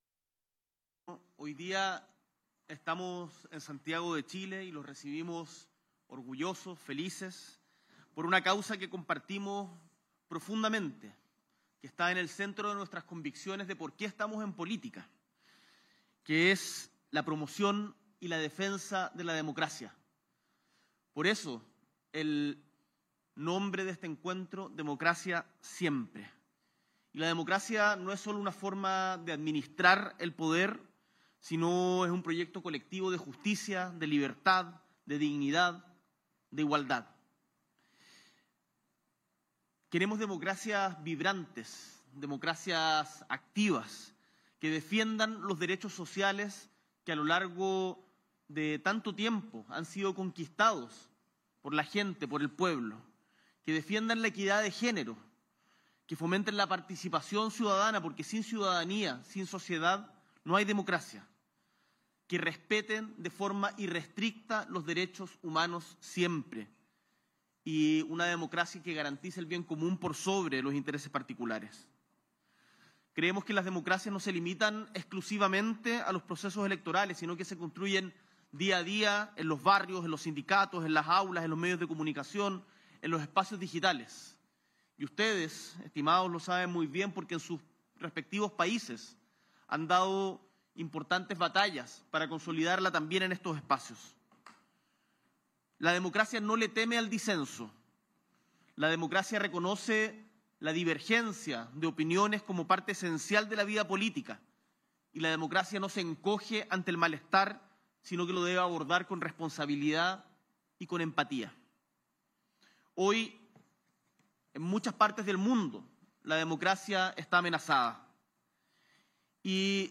Presidente de la República de Chile, Gabriel Boric Font, sostiene Reunión de Alto Nivel "Democracia Siempre"